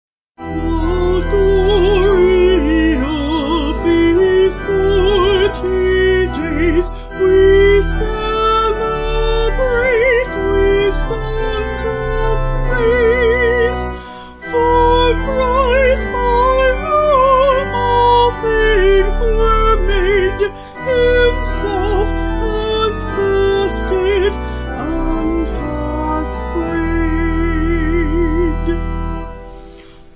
5/Em
Vocals and Organ